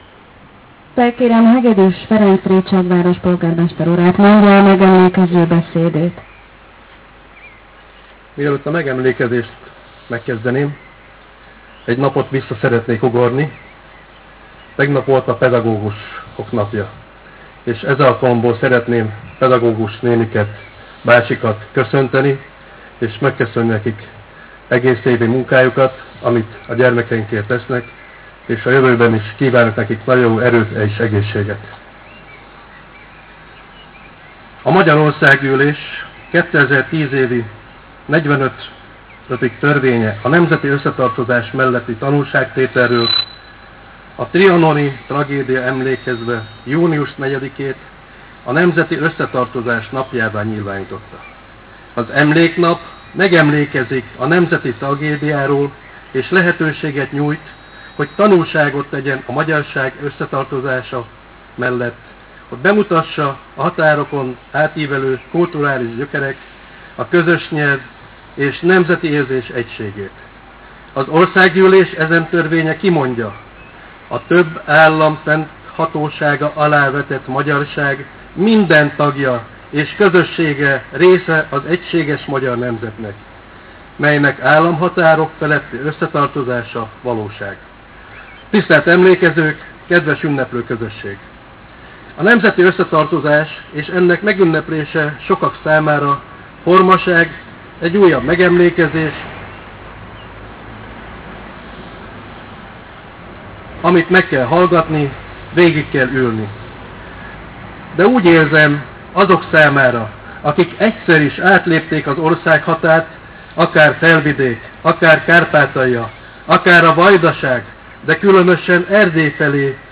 Rétságon is volt megemlékezés.
Hegedűs Ferenc polgármester mondott
megemlékező beszédet.